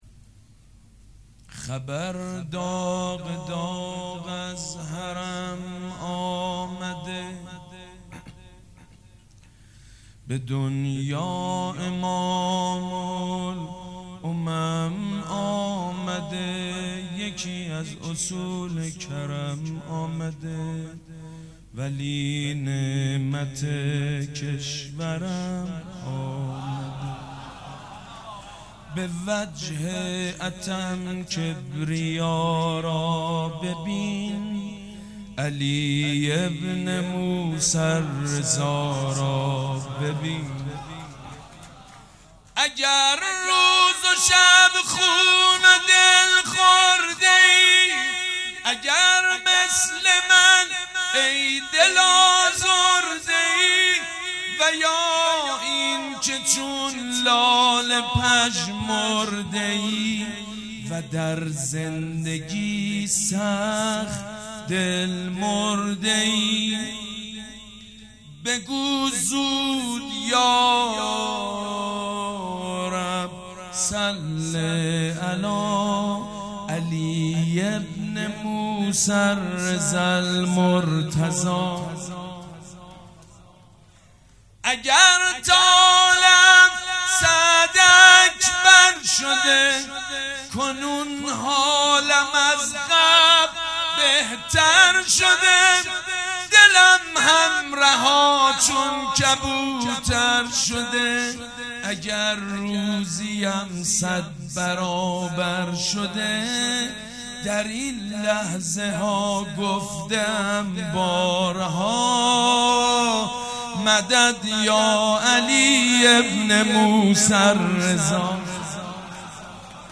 «میلاد امام رضا 1397» شعر خوانی: خبر داغ داغ از حرم آمده